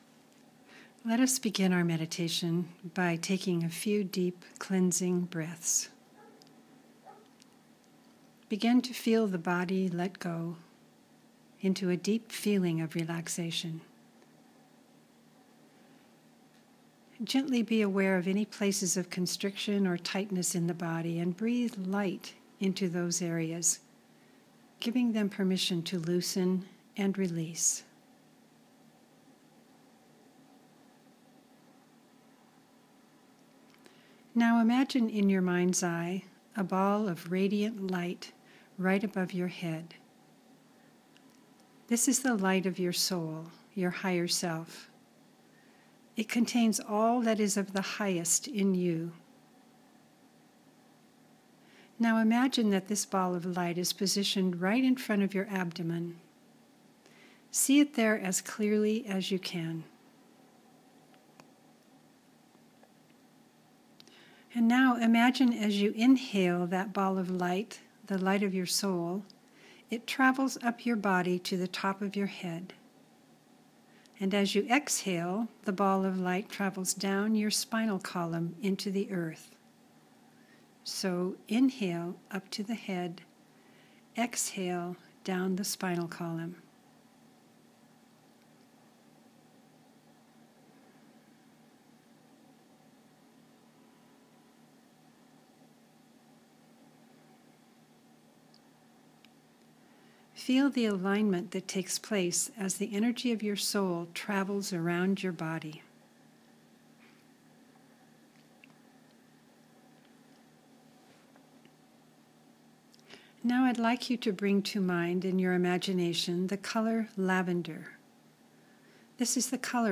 Have the Full Moon Ceremony and Guided Meditation sent to you each month.